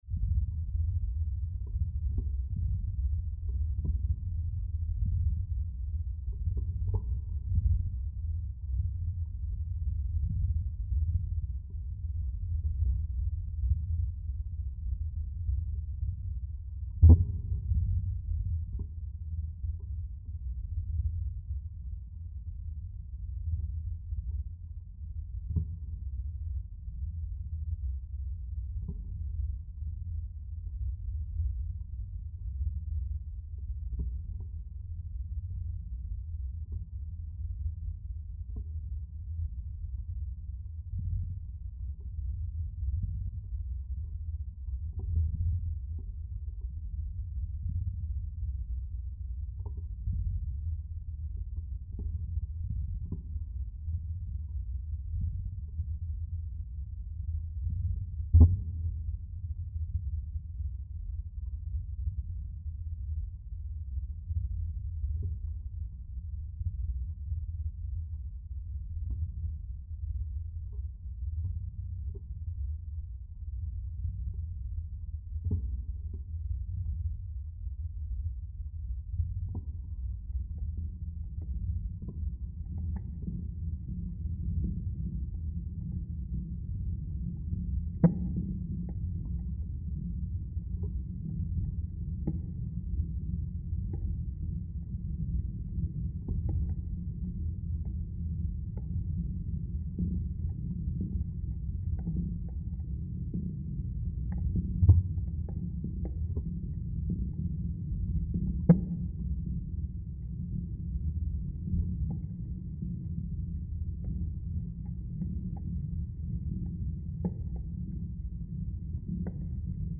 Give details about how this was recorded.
Hydrophone recording of shrimps in Canaveral Park reimagined